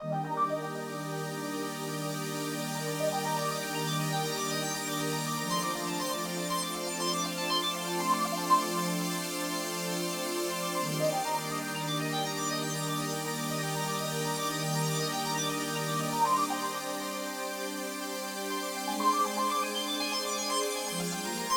03 rising pad A.wav